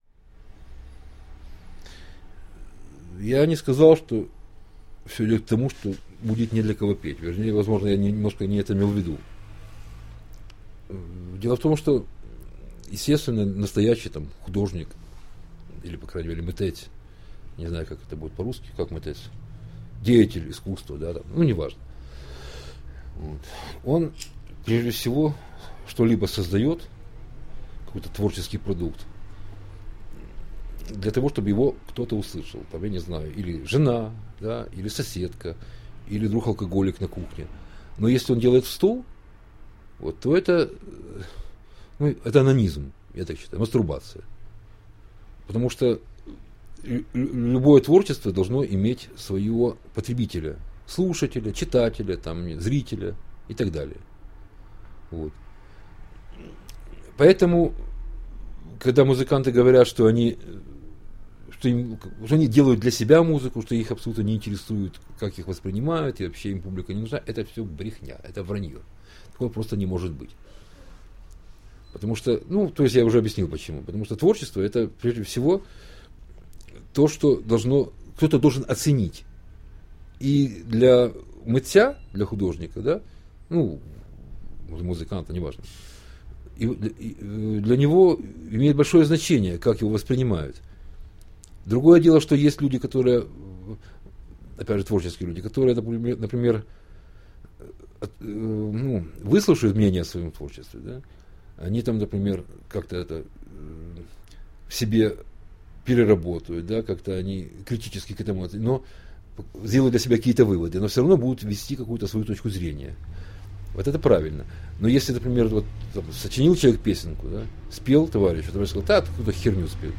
Да, впервые мы публикуем интервью не в виде текста, а в звуковом формате — мне кажется, такая подача информации намного интереснее.
Часть первая интервью